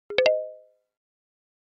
Звук сигнала быстрой загрузки